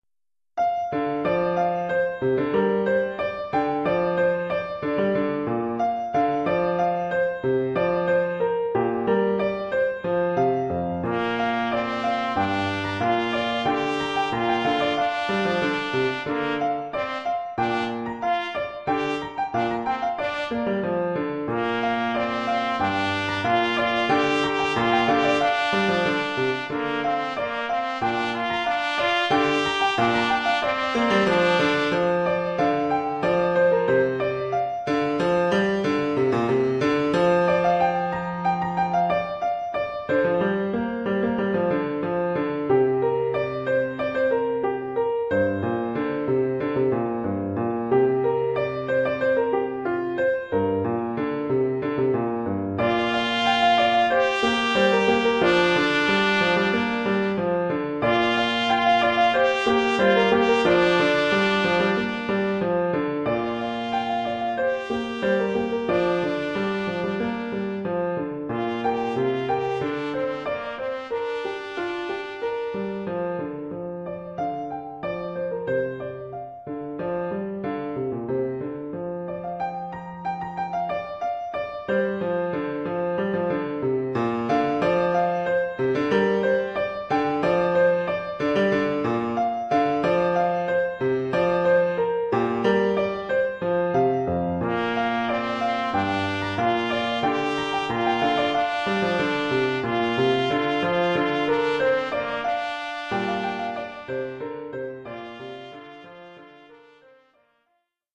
Oeuvre pour trompette ou cornet
ou bugle et piano.
Niveau : débutant.